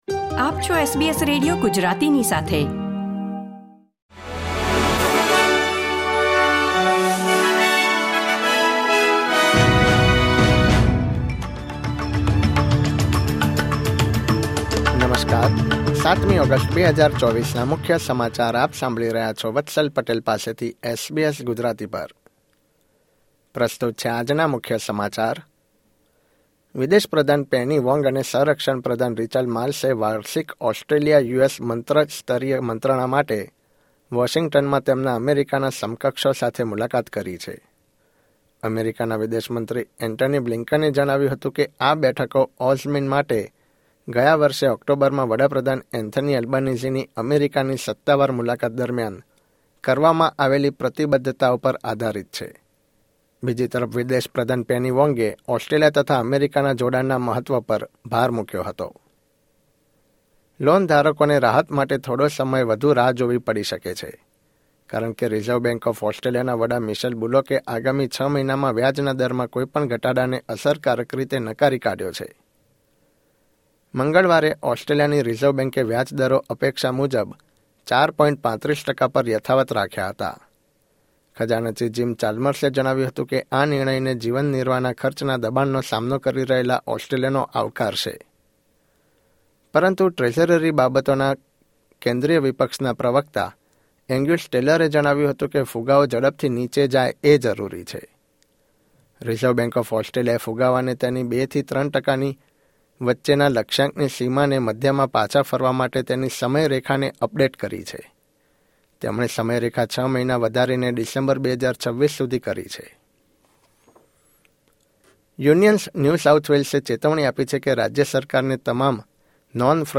SBS Gujarati News Bulletin 7 August 2024